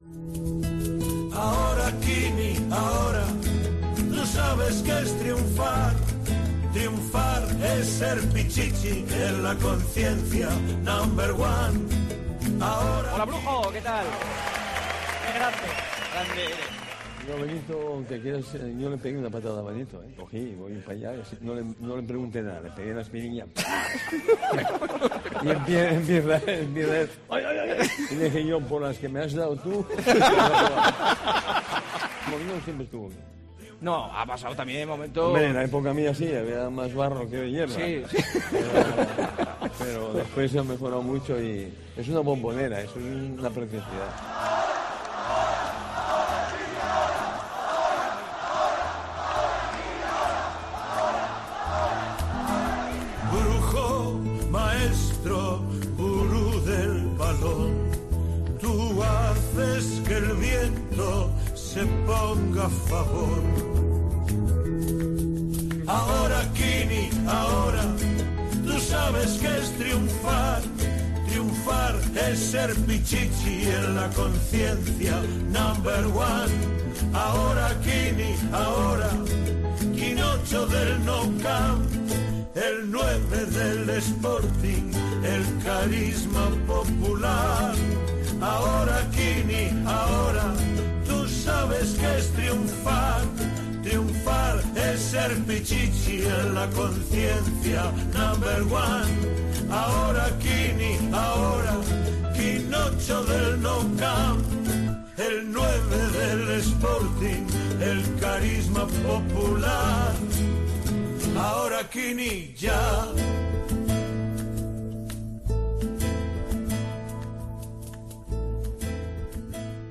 Quini, protagonista en El Partidazo de COPE en el Teatro Jovellanos de Gijón